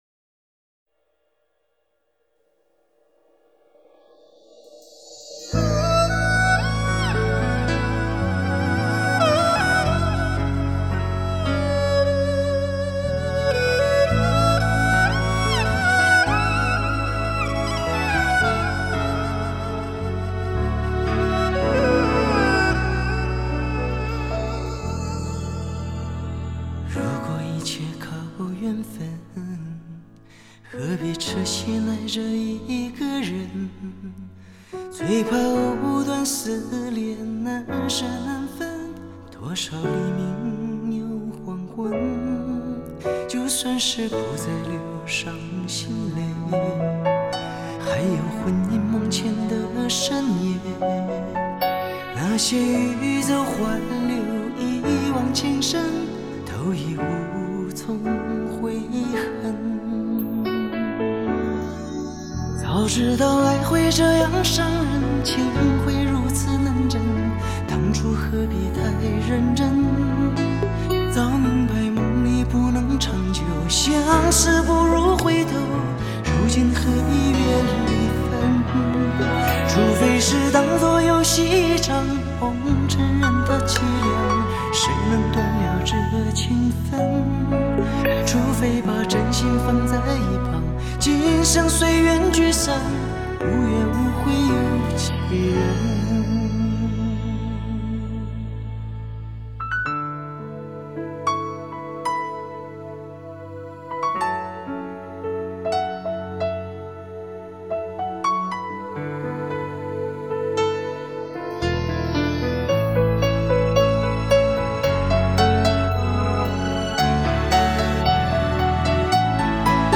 4/4 60以下